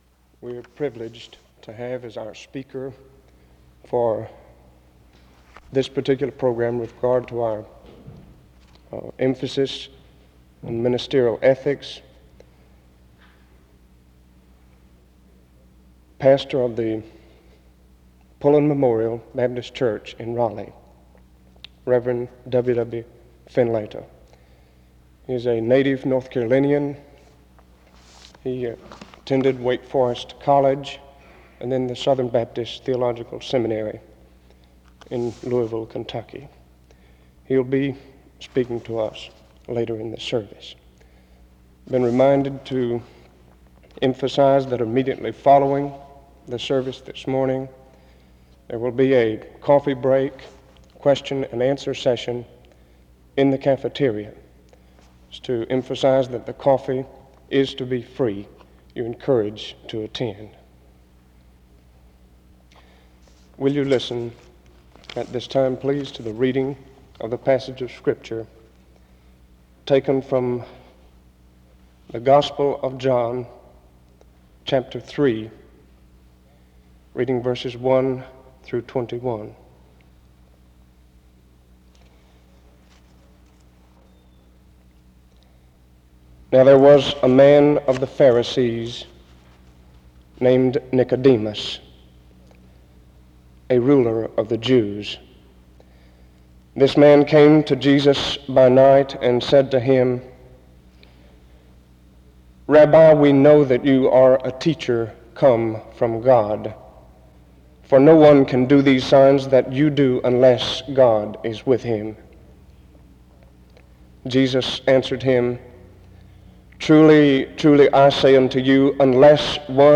The service begins with an introduction to the speaker and the reading of the source text, John 3:1-21.